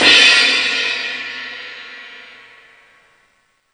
CRASH08   -L.wav